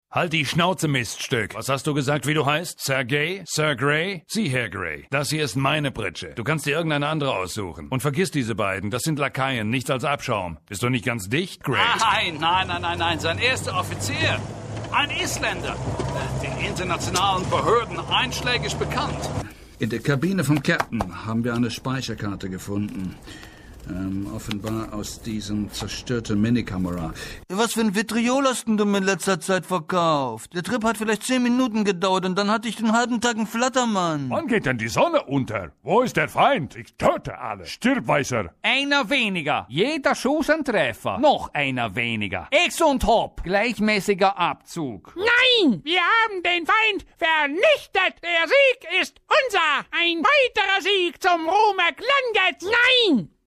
Games & Hoerspiel (Collage. Diverse Rollen)